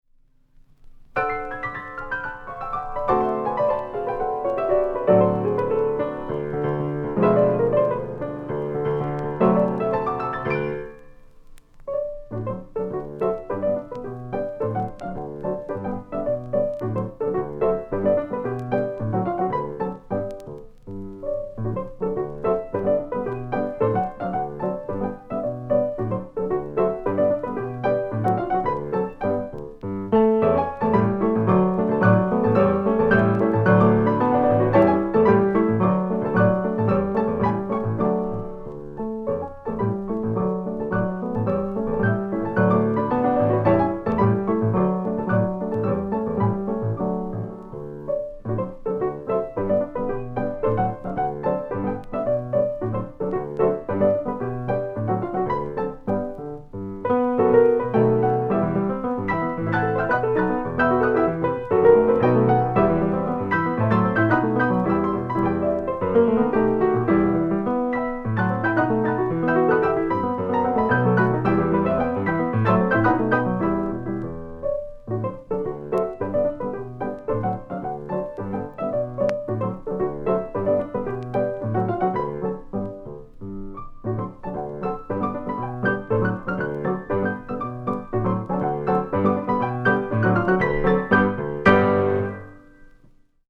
Sarau de Sinhá – Capricho